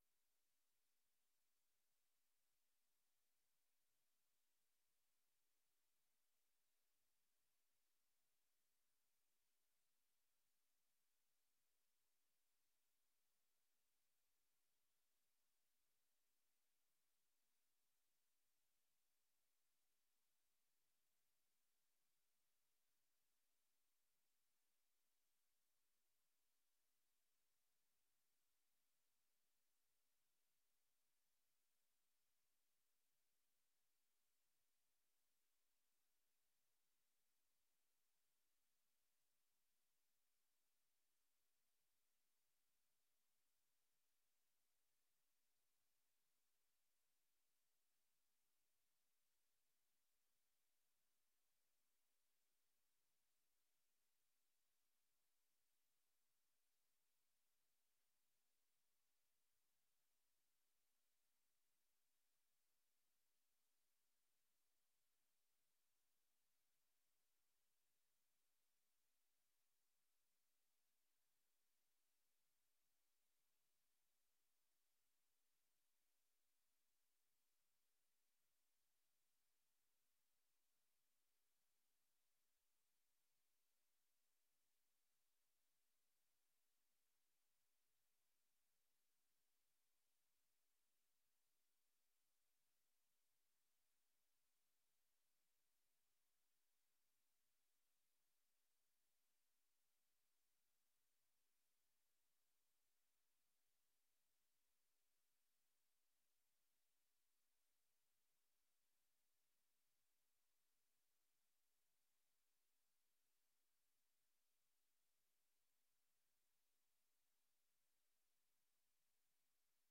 Download de volledige audio van deze vergadering
Praten met de Staten is de avond die inspraak in de commissievergaderingen vervangt.